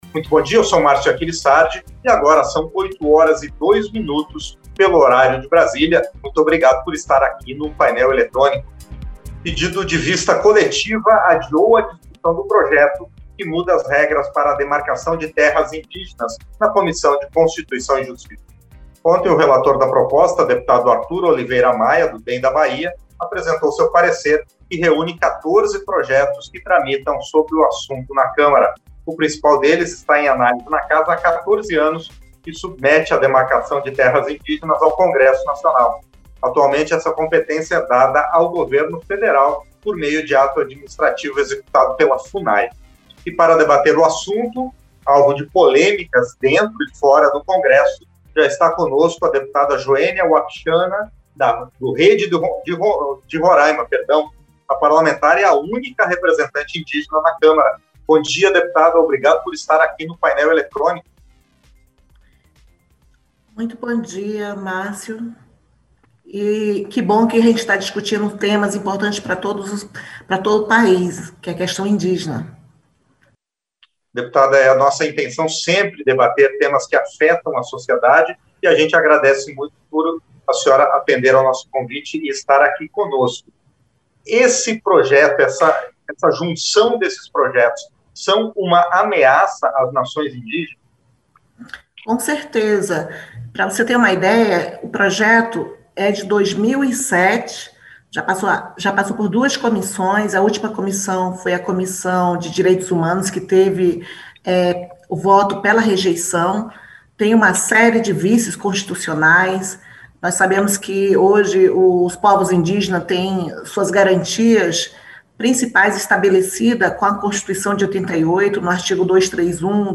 Entrevista - Dep.